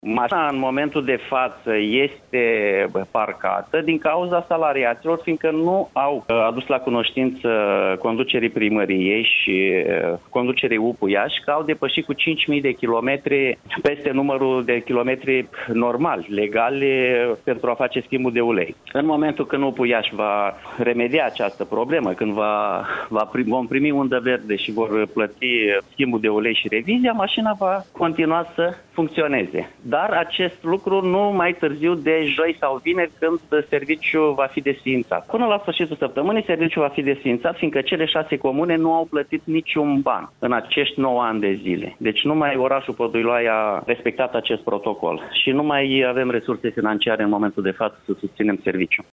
Mai mult, edilul a declarat pentru Radio Iaşi că substaţia SMURD din localitatea Podu Iloaiei va fi închisă la sfârşitul acestei săptămâni: